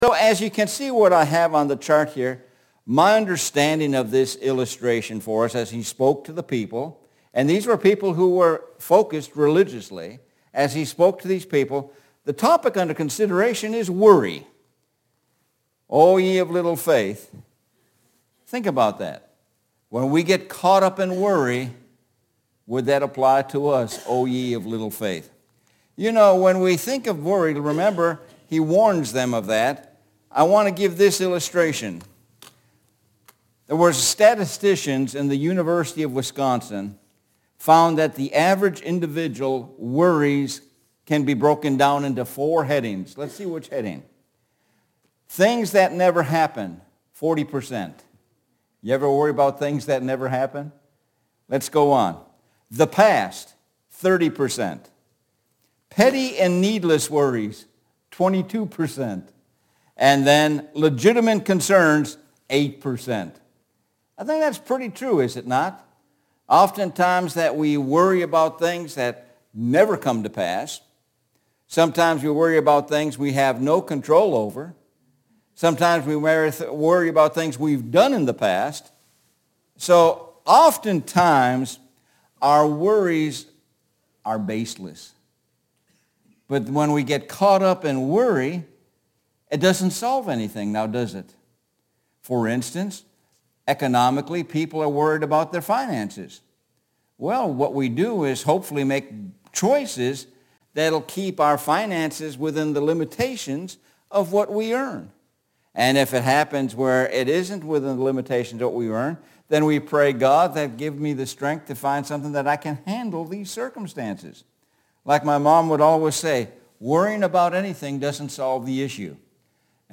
Sun AM Sermon – O Ye of Little Faith – 06.25.23